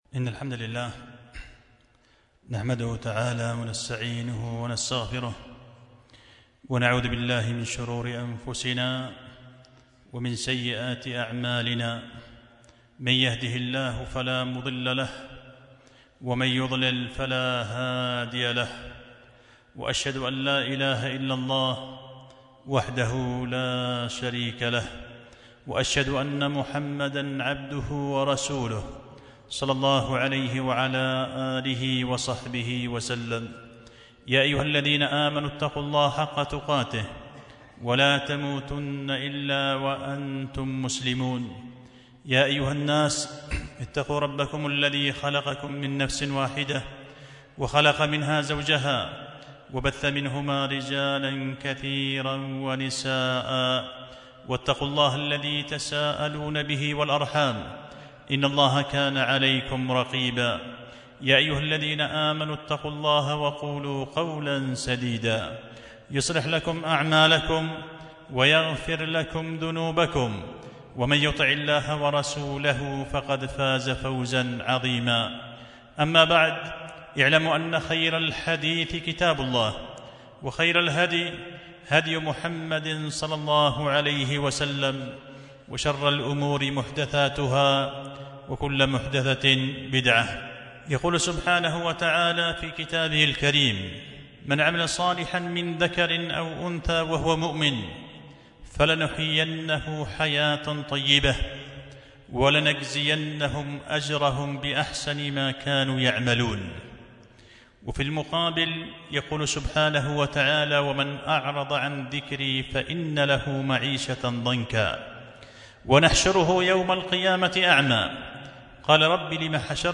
خطبة جمعة
مسجد الفاروق إب اليمن